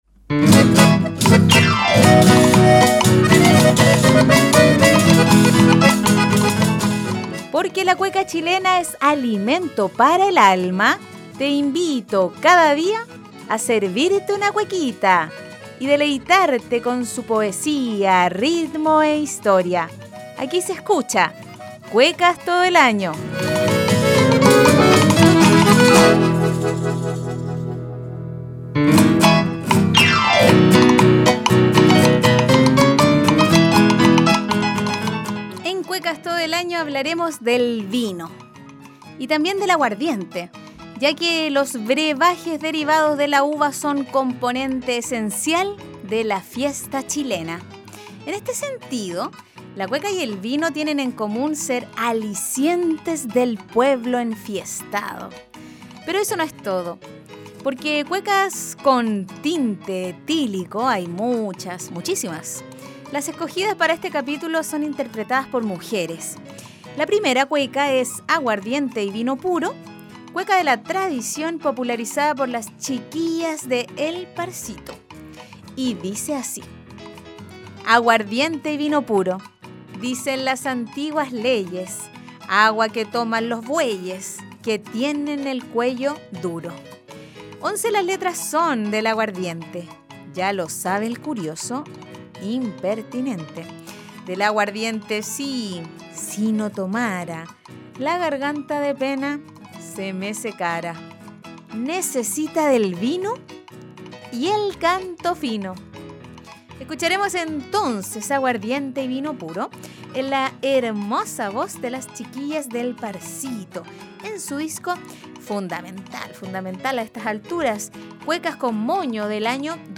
En el capítulo 32 de la primera temporada de “Cuecas todo el año”, nos deleitamos con cuecas dedicadas al vino y el aguardiente, pero además, cuecas cantadas por mujeres: Primero, “Aguardiente y vino puro” interpretada por El Parcito y, luego, “El vino” de “Calila Lila”.